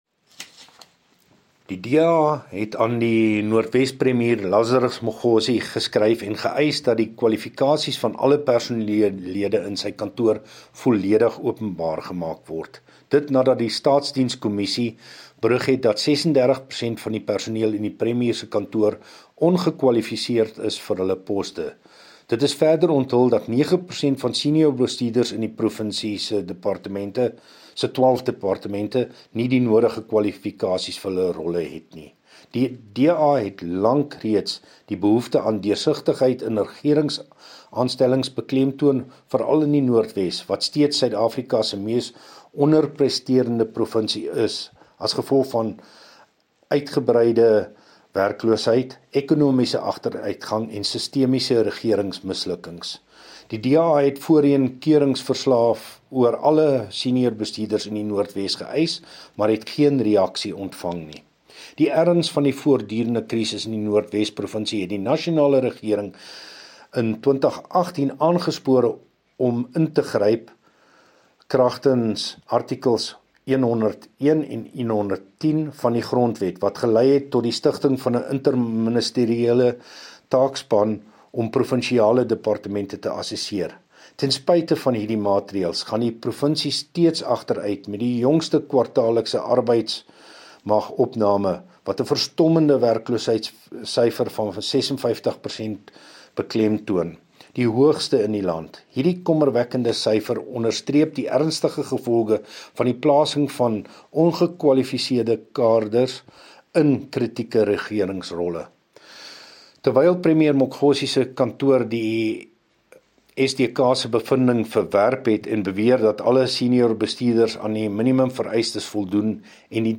Issued by Wolfgang Wallhorn – DA Spokesperson on the Office of the Premier in the North West Provincial Legislature
Note to Broadcasters: Please find attached soundbites in